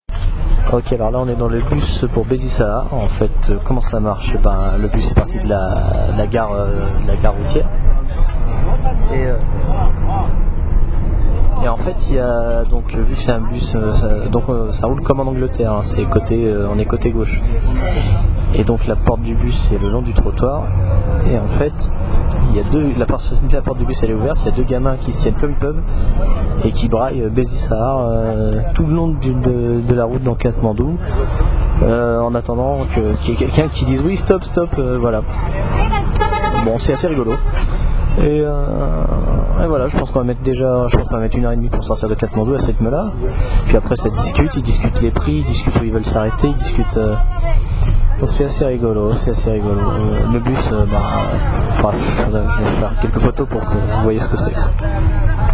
Bus-pour-Besi-Sahar.wma